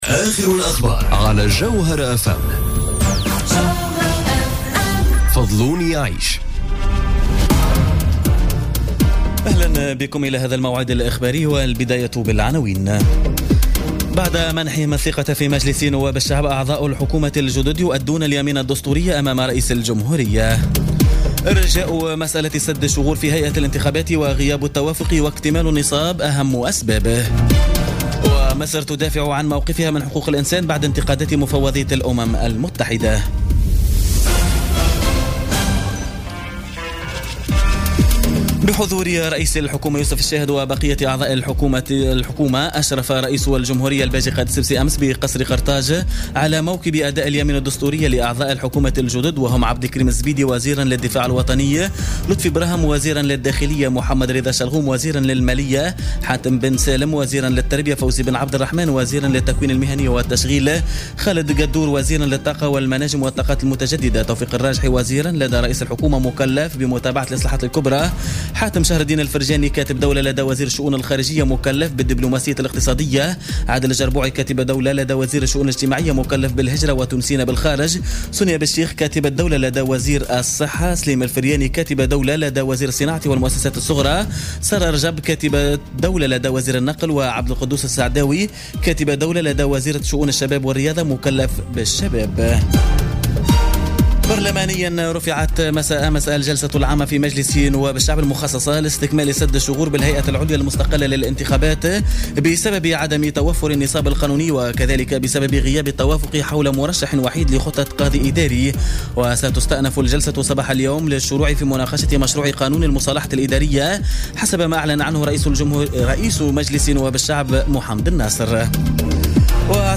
Journal Info 00h00 du mercredi 13 septembre 2017